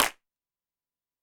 CLAP I.wav